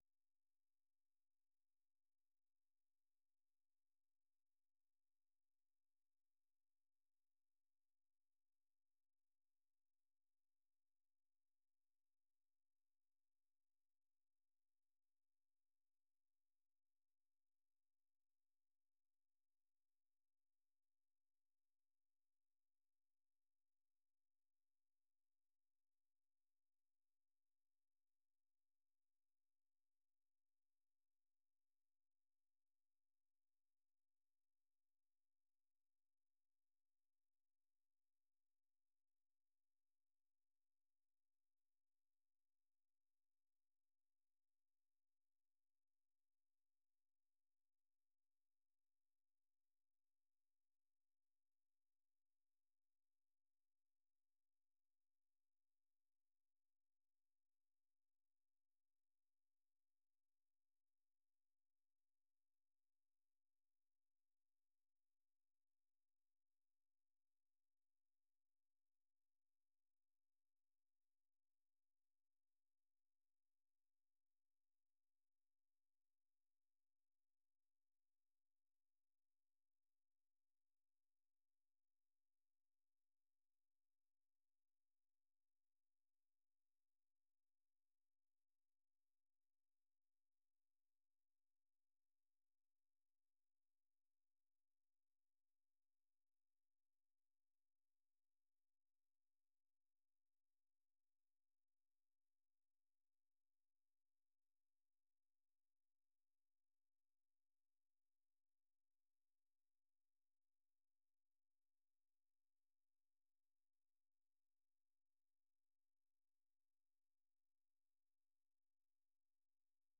The URL has been copied to your clipboard 페이스북으로 공유하기 트위터로 공유하기 No media source currently available 0:00 0:59:58 0:00 생방송 여기는 워싱턴입니다 생방송 여기는 워싱턴입니다 아침 공유 생방송 여기는 워싱턴입니다 아침 share 세계 뉴스와 함께 미국의 모든 것을 소개하는 '생방송 여기는 워싱턴입니다', 아침 방송입니다.